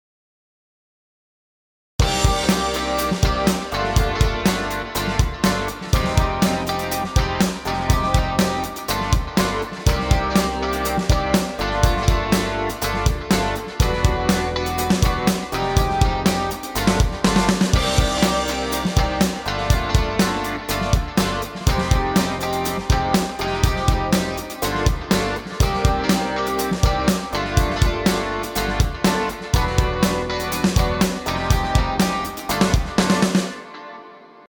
רוק.mp3 רוק ראשון שלי על הקיובייס הטראק עצמו הוא רק רעיון כנראה שאני אבנה עליו יותר…(עם איזה גיטרה סולו הבעיה שגיטרות וקיובייס פחות הולך ביחד…)
שגיטרות וקיובייס פחות הולך ביחד